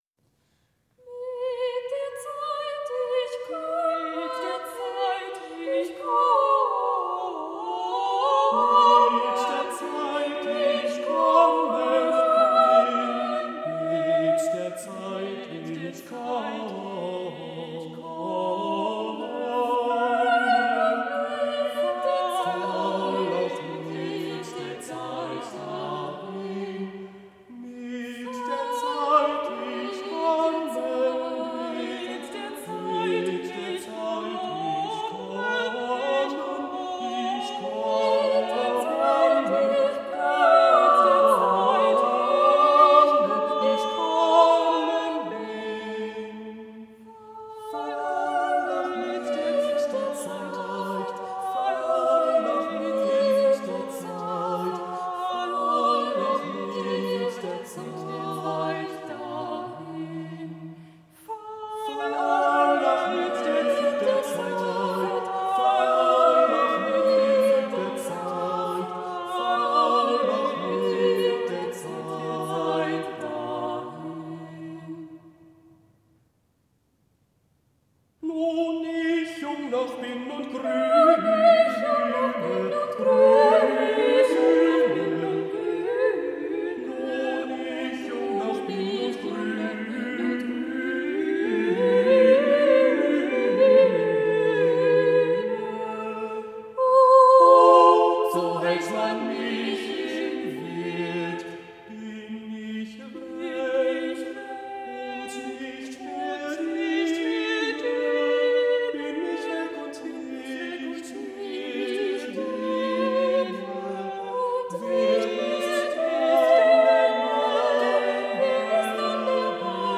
Musicalische Kürbs-Hütte : Welche vns erinnert Menschlicher Hinfälligkeit : geschrieben vnd Jn 3. Stimmen gesetzt, 1645
Diese Aphorismen vertonte Heinrich Albert und veröffentlichte den filigranen, dreistimmigen Zyklus »Musikalische Kürb(i)shütte, welche uns erinnert menschlicher Hinfälligkeit« (1641) – der Lyriker und Dichter Johannes Bobrowski (1917–1965), 1928–1939 ebenfalls in Königsberg wohnend, schreibt ehrfürchtig von diesem Kleinod, das unter anderem diese Sinnsprüche beinhaltet:
Hier verbindet er sowohl französische als auch polnische und italienische Einflüsse in Bezug auf Wortgebundenheit, tänzerischen Rhythmus und perlende Koloratur.